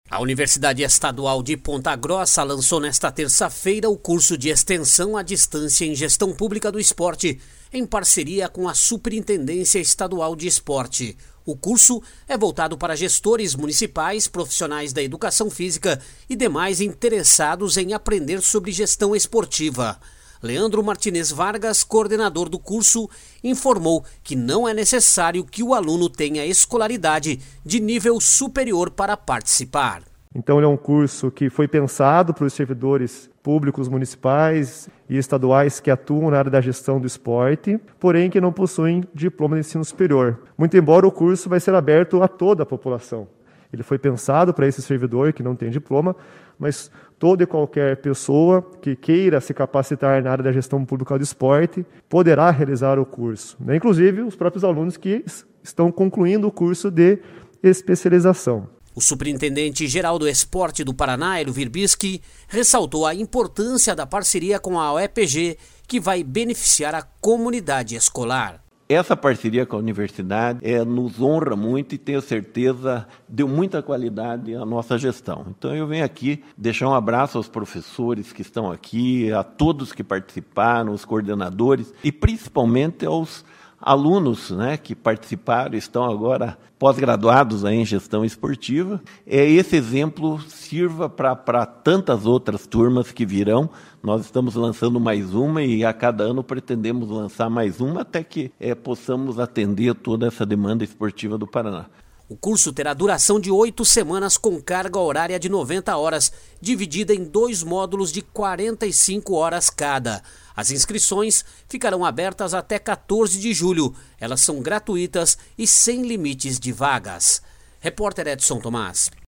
// SONORA HELIO WIRBISKI .// O curso terá duração de oito semanas, com carga horária de 90 horas, dividida em dois módulos de 45 horas cada.